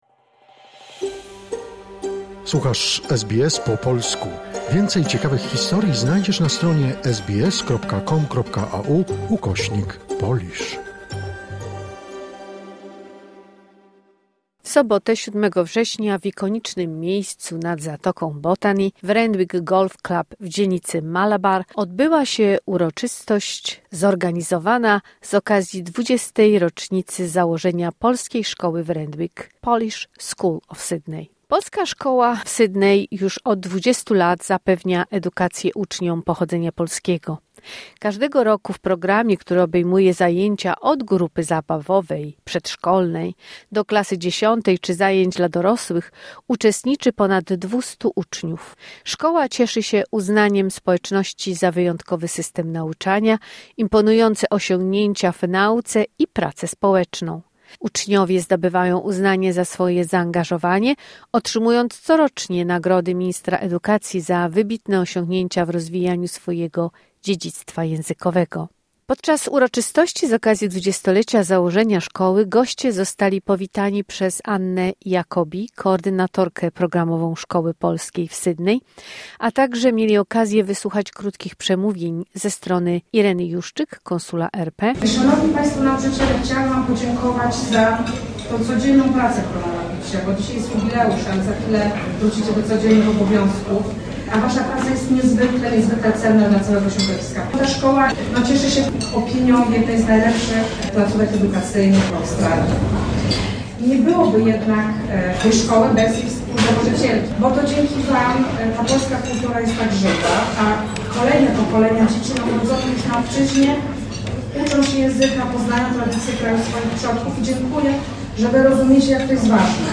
W sobotę 7 września w ikonicznych miejscu nad zatoką Botany, w Randwick Golf Club w dzielnicy Malabar, odbyła się uroczystość zorganizowana z okazji 20. rocznicy założenia Polskiej Szkoły w Randwick, Polish School of Sydney .